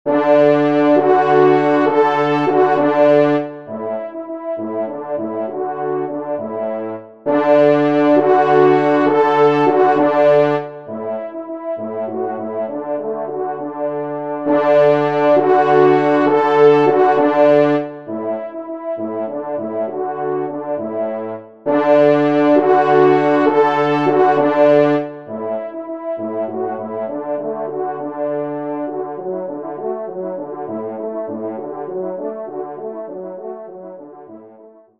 3e Trompe
Millescamps-AFT-10-Indiana_TRP-3_EXT.mp3